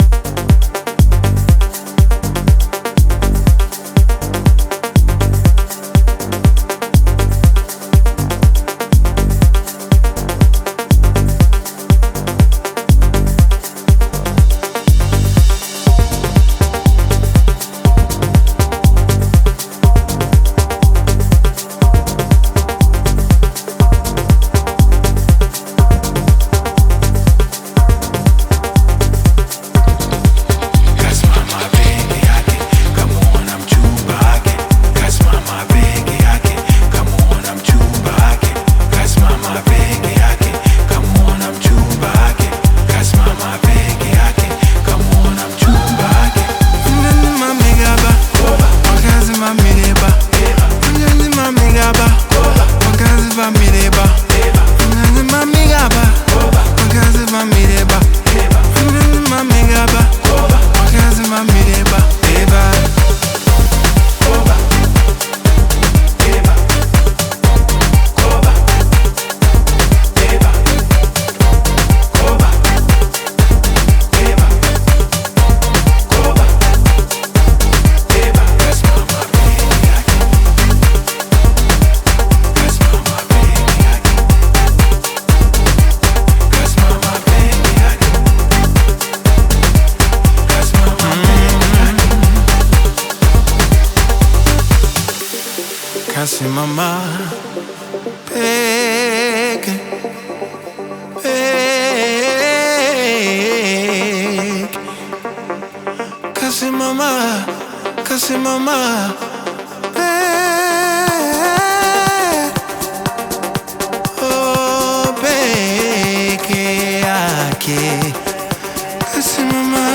smooth Afro-House/Bongo Flava single